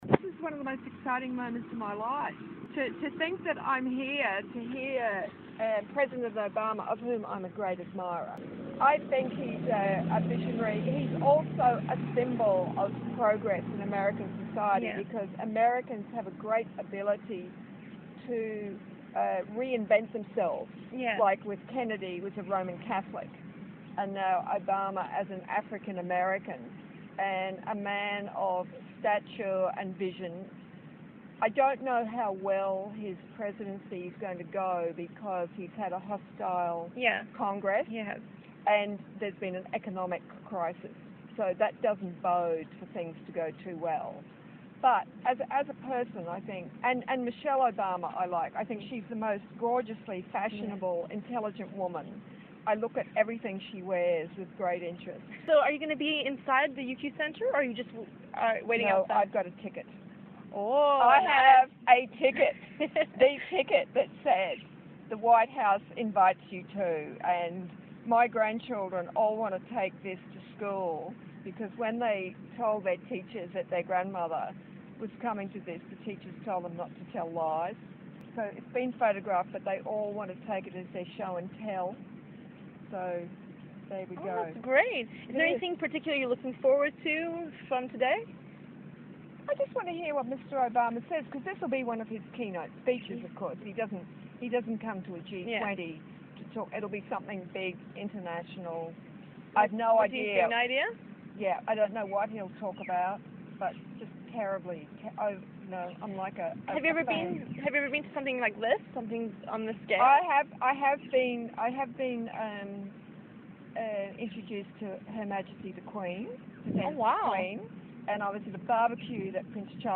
At Barrack Obama's speech